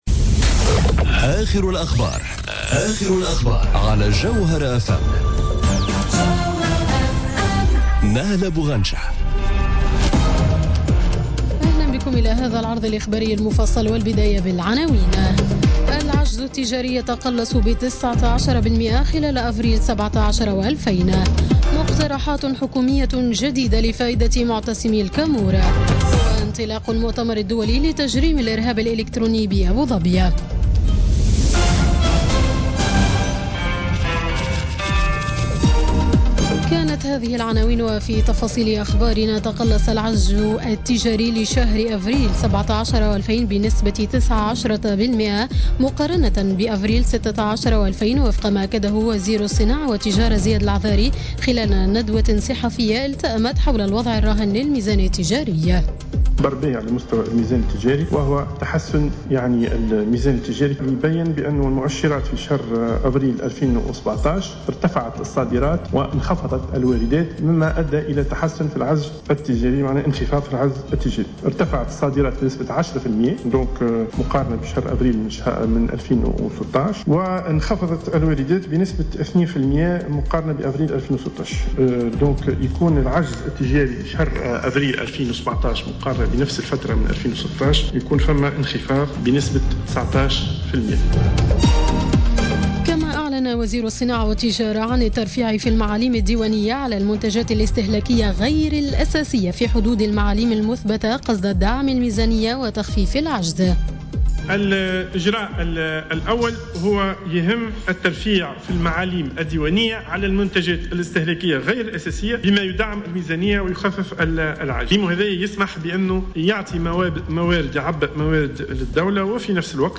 نشرة أخبار السابعة مساء ليوم الاثنين 15 ماي 2017